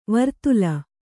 ♪ vartula